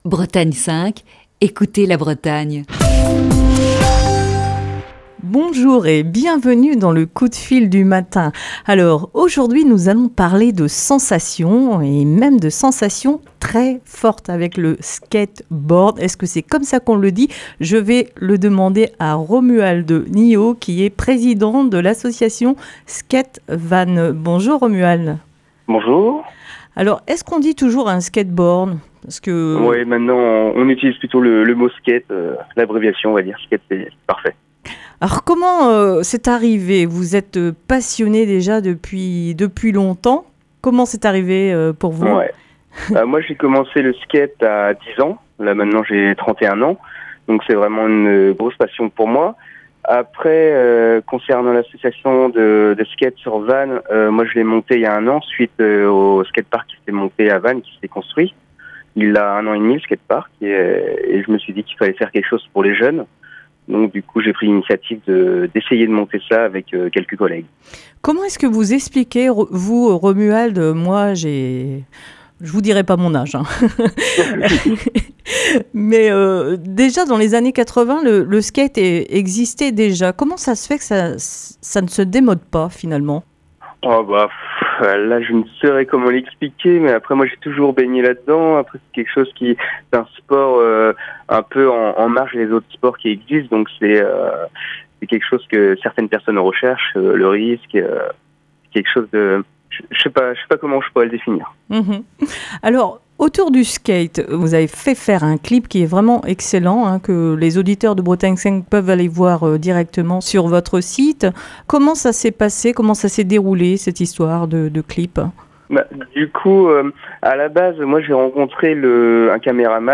Pour ce dernier Coup de fil du matin de la semaine